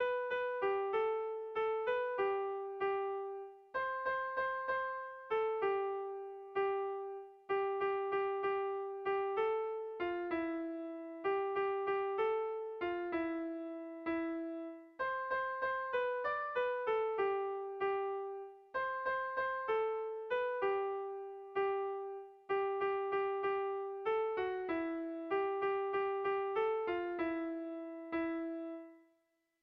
Kontakizunezkoa
Zortziko txikia (hg) / Lau puntuko txikia (ip)
ABDB